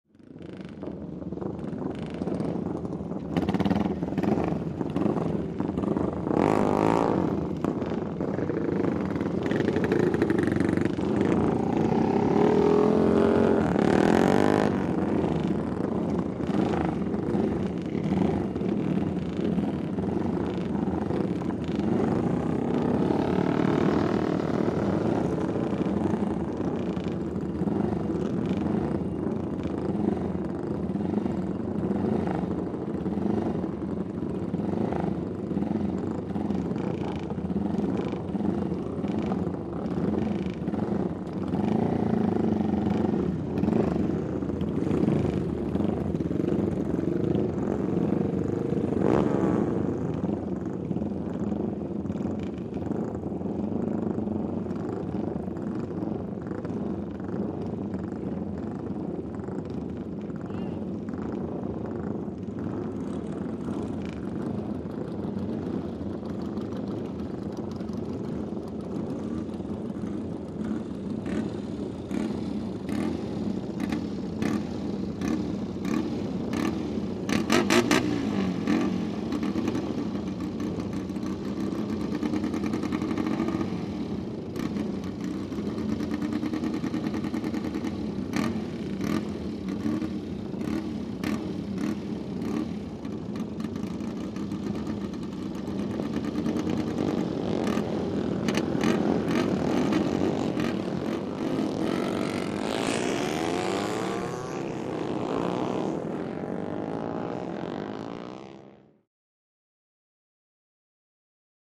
Motorcycle; Several Four Stroke Dirt Bikes Idle, Rev, And Away, Medium Pov.